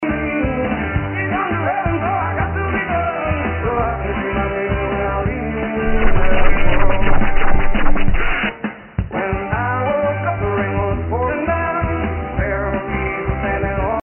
~All tracks are Remixes, unless otherwise stated~